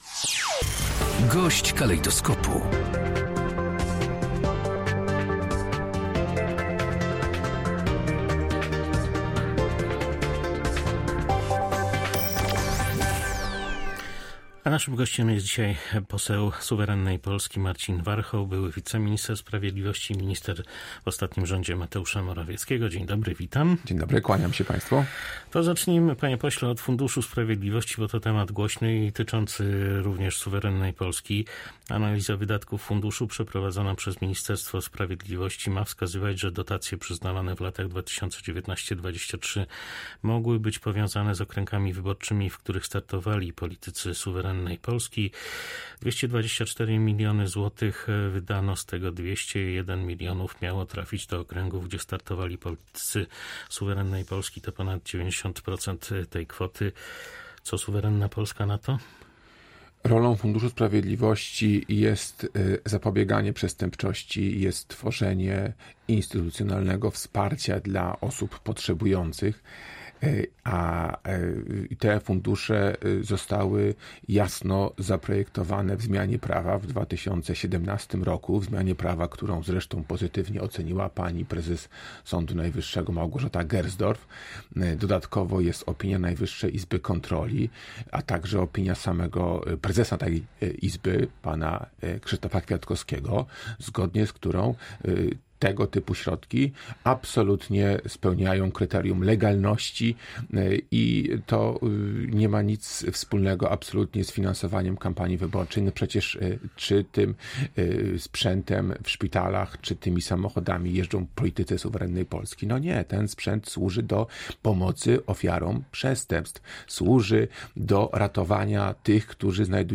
Wszystkie środki z Funduszu Sprawiedliwości były wydatkowane zgodnie z założeniami. Nie było zastrzeżeń ze strony Najwyższej Izby Kontroli i samego Krzysztofa Kwiatkowskiego – mówił nasz gość Marcin Warchoł poseł Suwerennej Polski, odnosząc się do zarzutów kierowanych do posłów Suwerennej Polski, że pieniądze przekazywane były według politycznego uznania.
Służy ofiarom przestępstw i tym, którzy znajdują się w stanie zagrożenia życia lub zdrowia – argumentował poseł na naszej antenie.